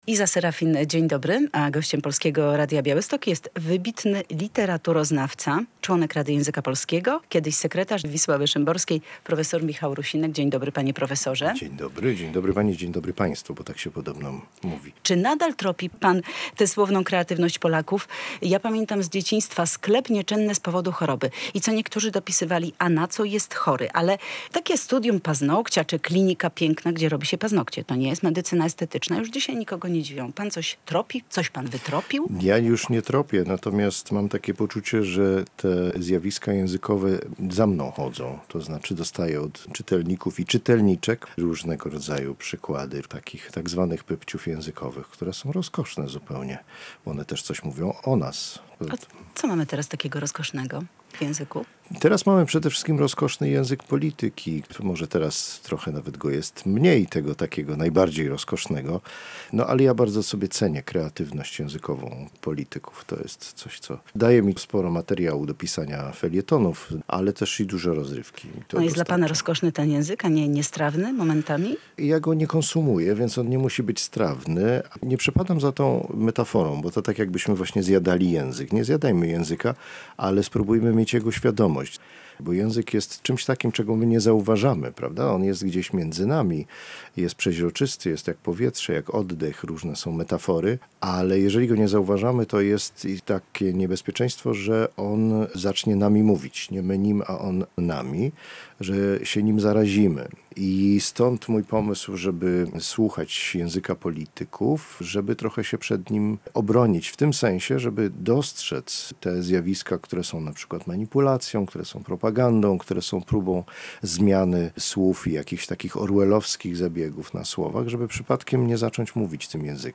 Radio Białystok | Gość | prof. Michał Rusinek [wideo] - literaturoznawca, członek Rady Języka Polskiego, pisarz i tłumacz